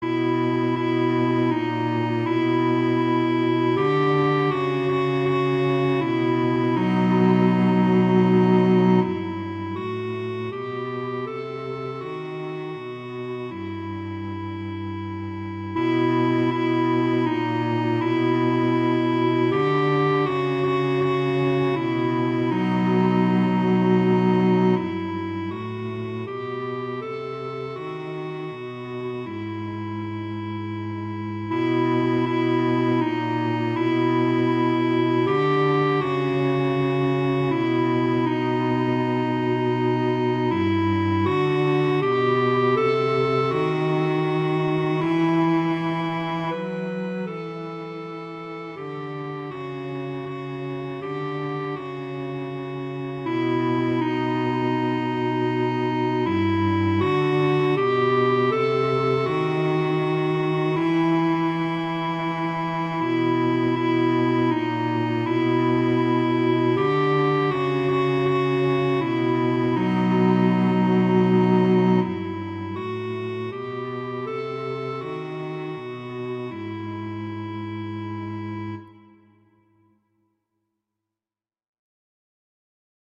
arrangements for clarinet and cello